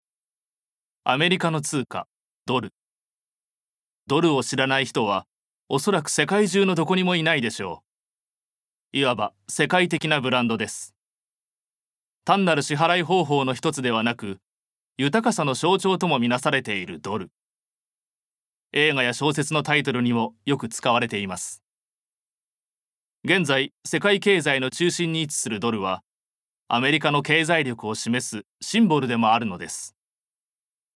ボイスサンプル
ナレーション４